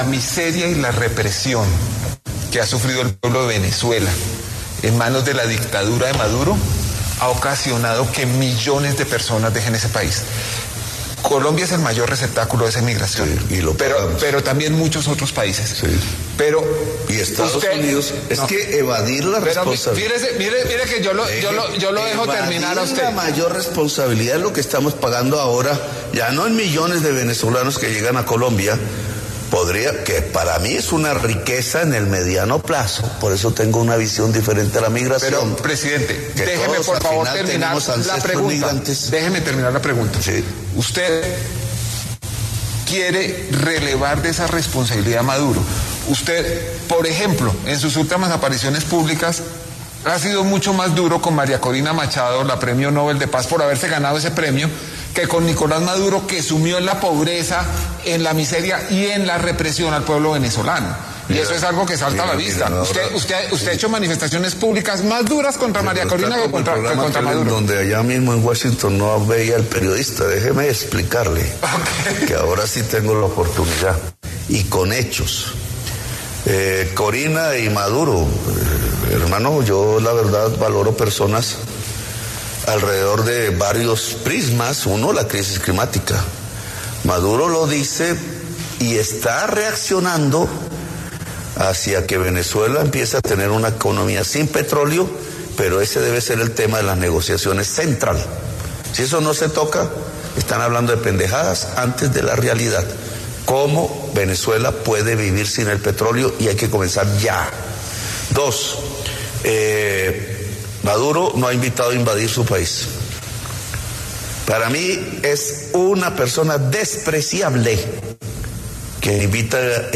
El presidente Petro también se refirió a María Corina Machado en su entrevista con Daniel Coronell.